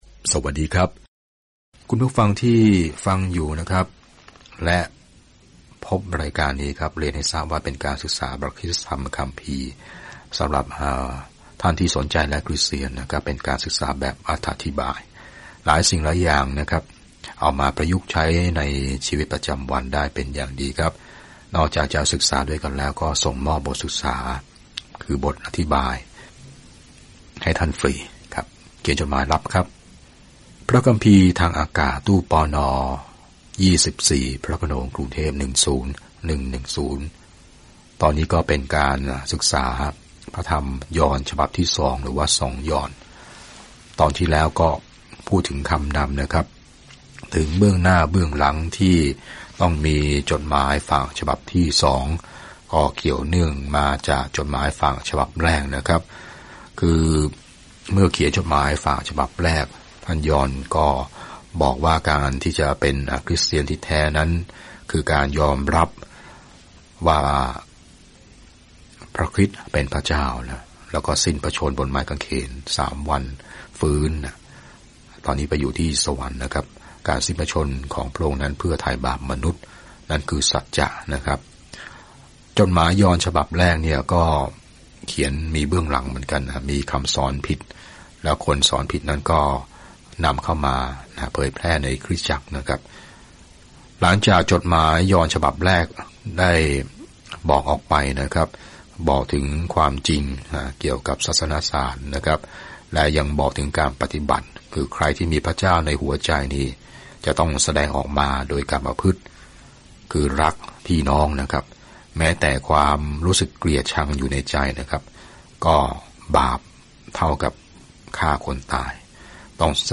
จดหมายฉบับที่สองจากยอห์นนี้ช่วยให้ผู้หญิงที่มีน้ำใจและคริสตจักรท้องถิ่นรู้วิธีแสดงความรักภายในขอบเขตของความจริง เดินทางทุกวันผ่าน 2 ยอห์นในขณะที่คุณฟังการศึกษาด้วยเสียงและอ่านข้อที่เลือกจากพระวจนะของพระเจ้า